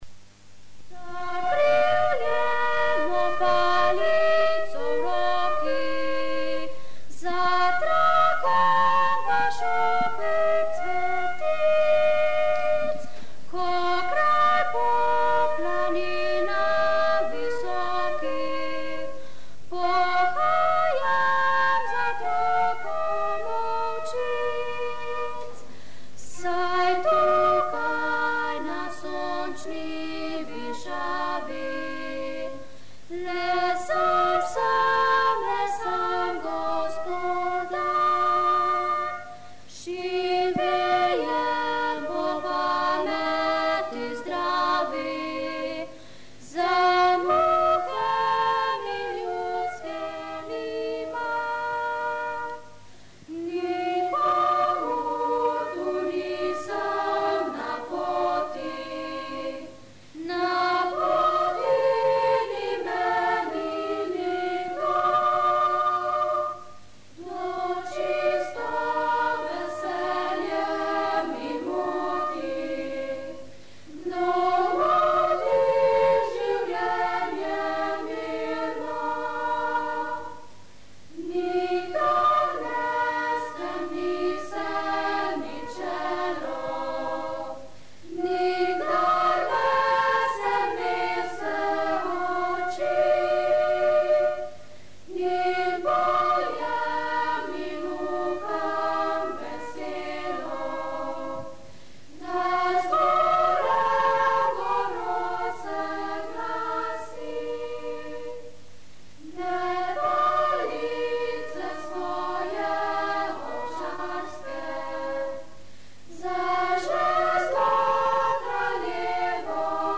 uk.gif (7420 bytes)A folk song about nature
Skupina deklet je skupaj s učiteljico glasbe vadila in pesem posnela.